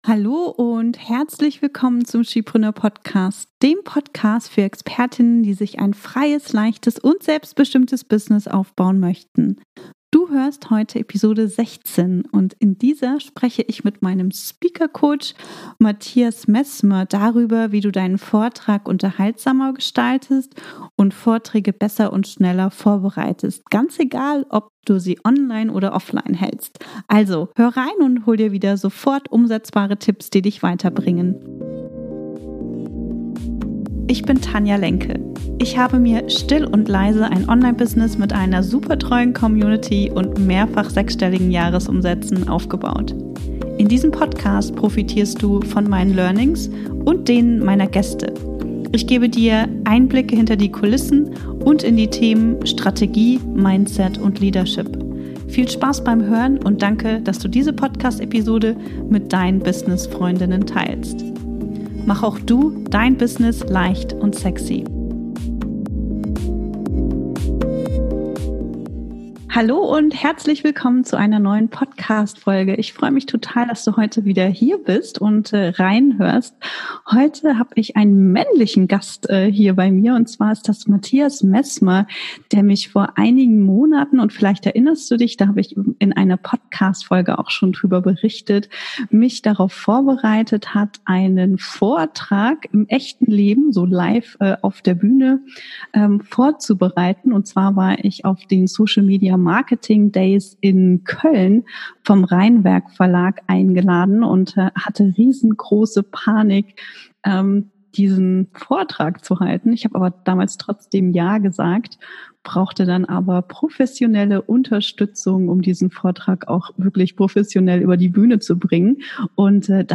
Wie du Vorträge unterhaltsamer gestaltest - Gespräch